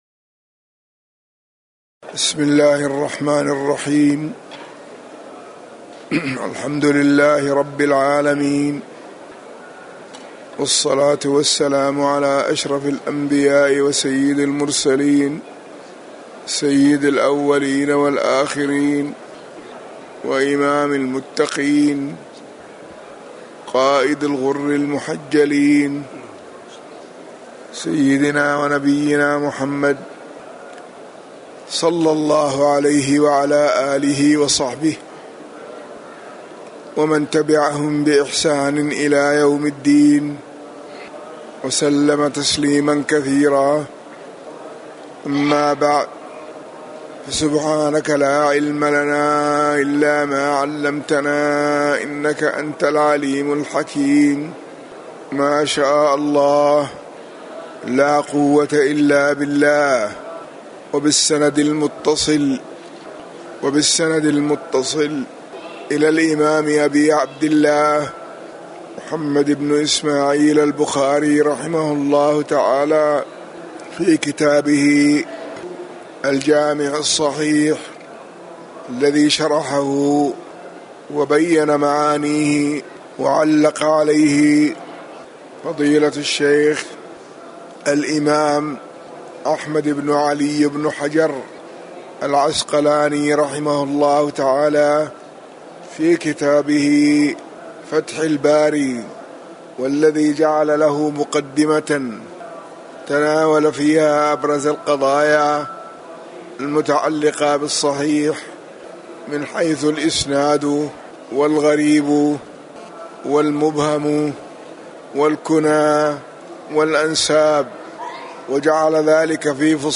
تاريخ النشر ٢٧ محرم ١٤٤٠ هـ المكان: المسجد النبوي الشيخ